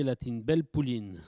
Langue Maraîchin
locutions vernaculaires